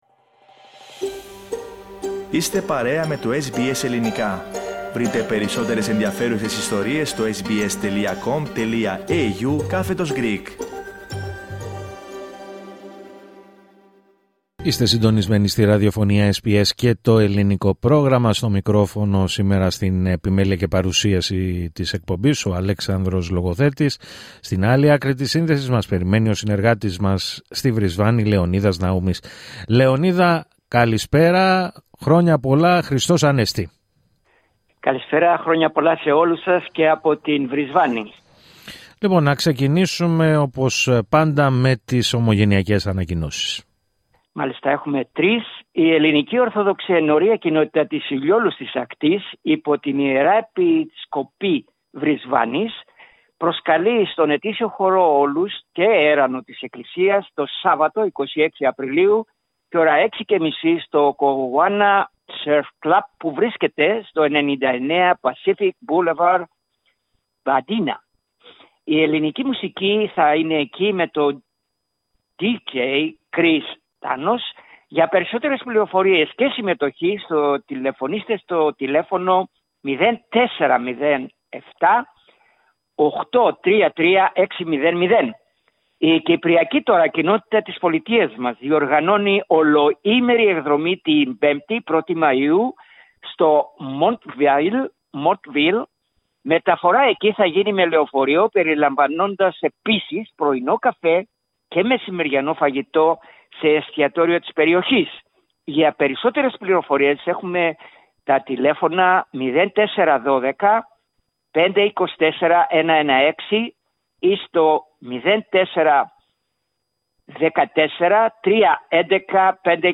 Ακούστε τα υπόλοιπα θέματα της ανταπόκρισης από την Βρισβάνη, πατώντας PLAY δίπλα από την κεντρική φωτογραφία.